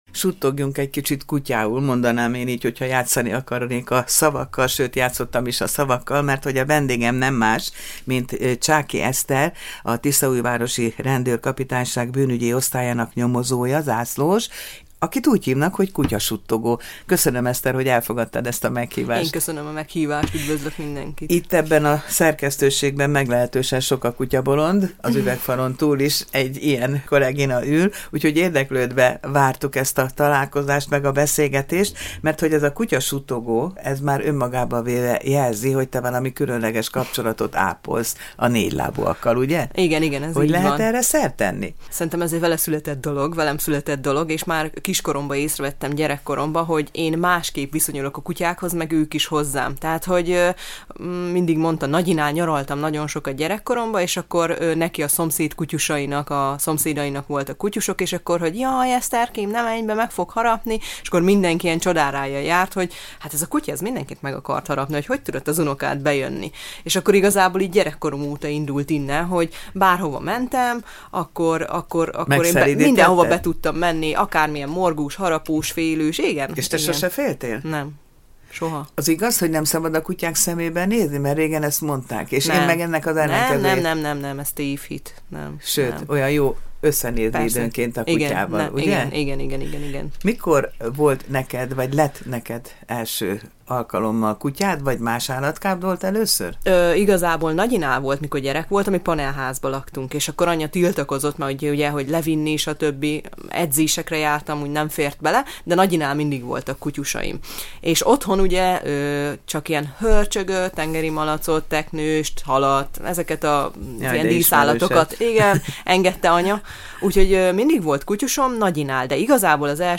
beszélgetett a Csillagpont Rádió műsorában.